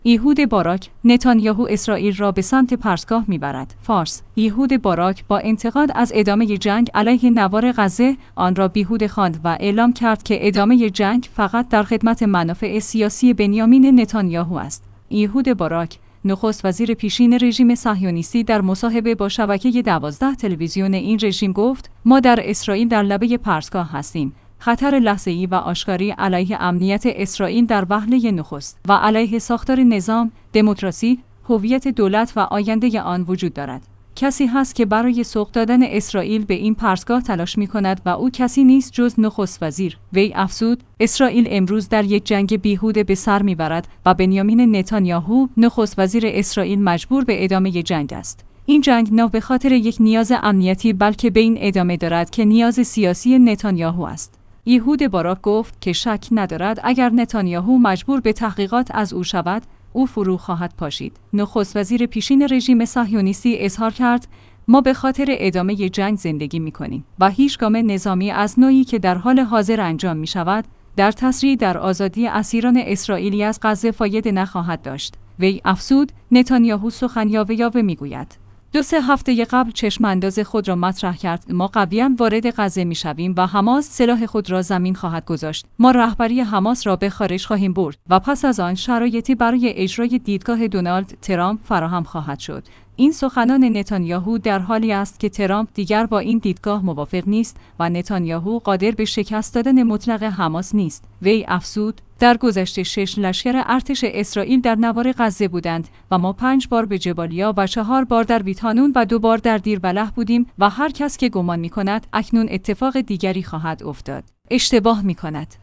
فارس/ ایهود باراک با انتقاد از ادامه جنگ علیه نوار غزه، آن را بیهوده خواند و اعلام کرد که ادامه جنگ فقط در خدمت منافع سیاسی بنیامین نتانیاهو است. ایهود باراک، نخست وزیر پیشین رژیم صهیونیستی در مصاحبه با شبکه ۱۲ تلویزیون این رژیم گفت: ما در اسرائیل در لبه پرتگاه هستیم، خطر لحظه‌ای و آشکاری علیه امنیت